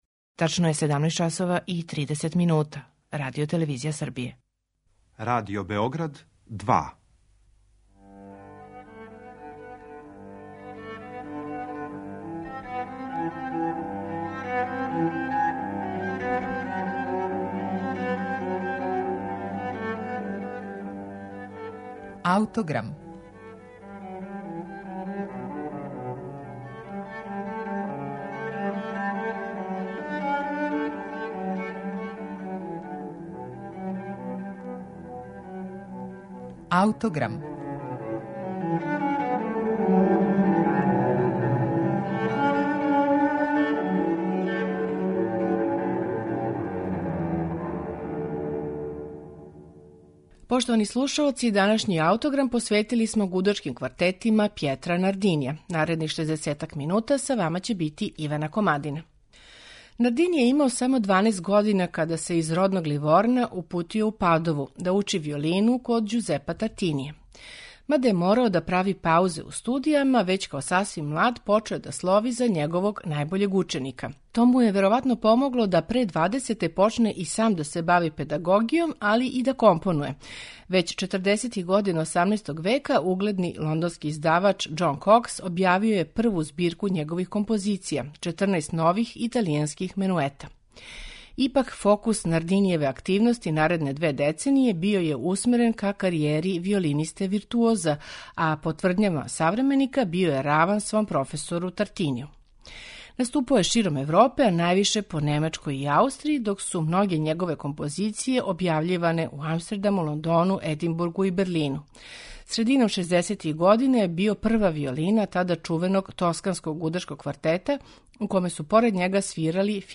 ПЈЕТРО НАРДИНИ: ГУДАЧКИ КВАРТЕТИ
У данашњем Аутограму Нардинијеве гудачке квартете слушамо у интерпретацији Квартета „Eleusi".